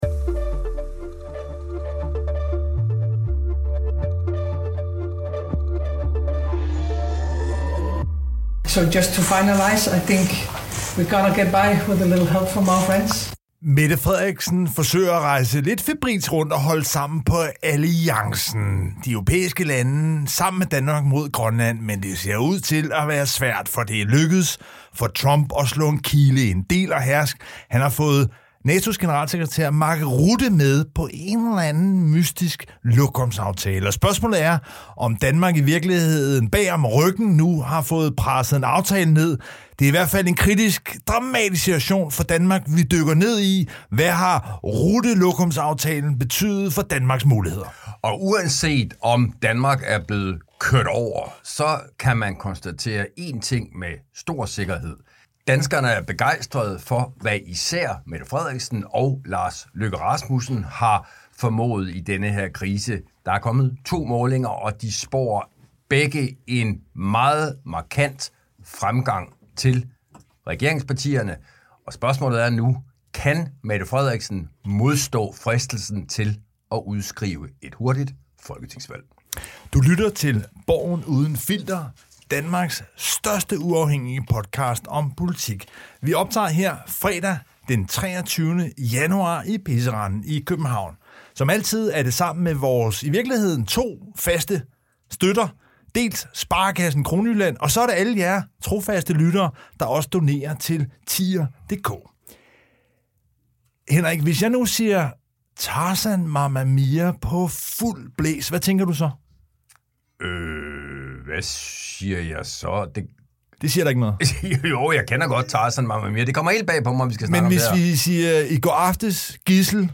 De to politiske kommentatorer Lars Trier Mogensen og Henrik Qvortrup analyserer ugens vigtigste begivenheder.